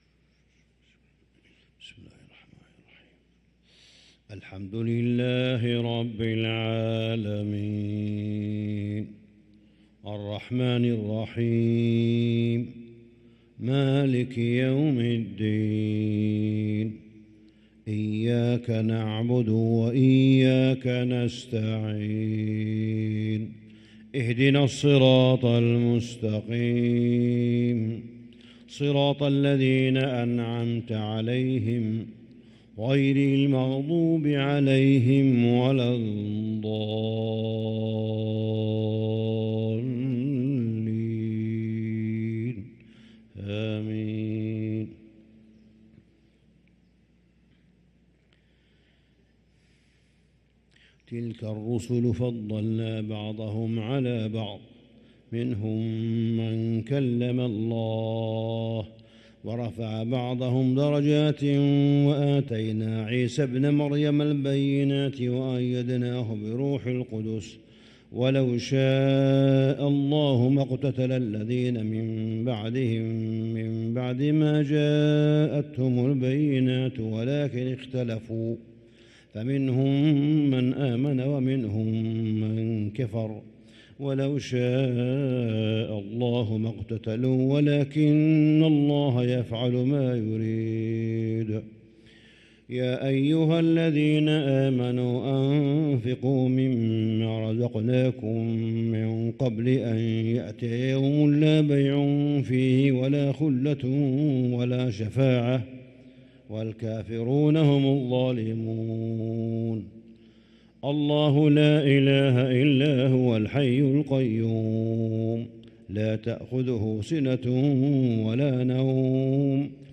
صلاة الفجر للقارئ صالح بن حميد 24 صفر 1445 هـ